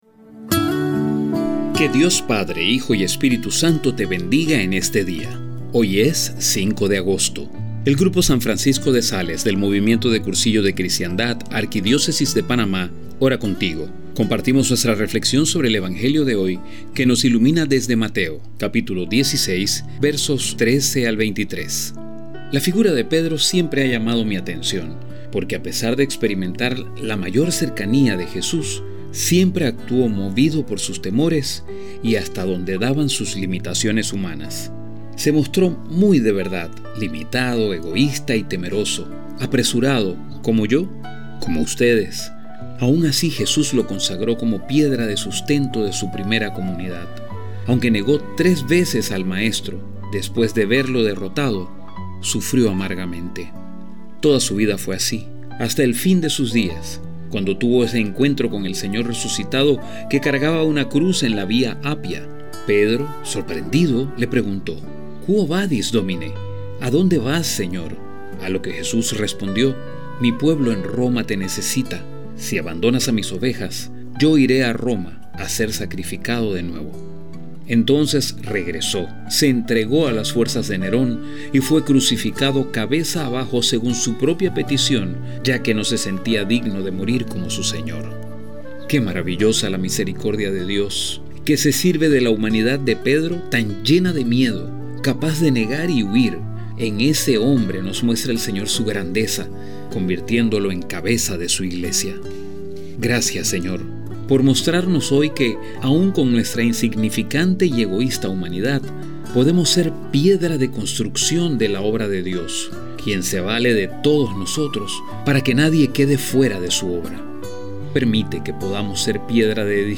A continuación la audioreflexión preparada por el grupo «San Francisco de Sales» del Movimiento de Cursillos de Cristiandad de la Arquidiócesis de Panamá, junto a una imagen para ayudarte en la contemplación.